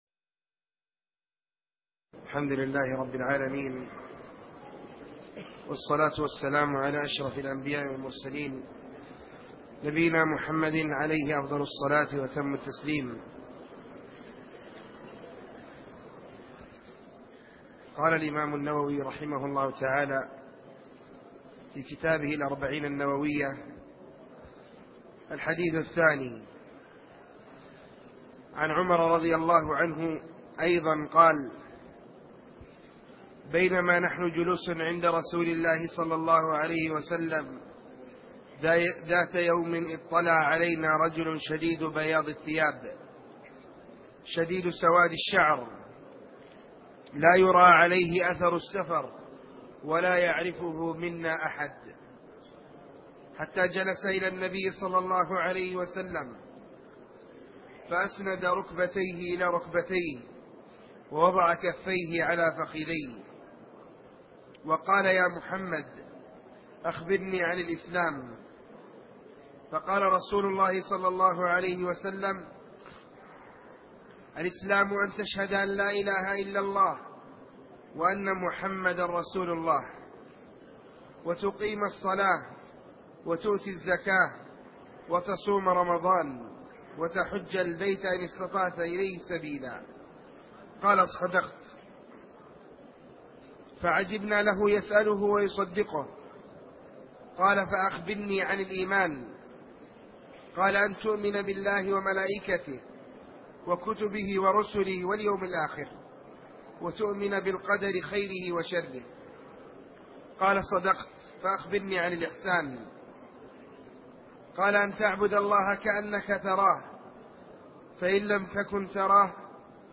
شرح الأربعون النووية الدرس الثاني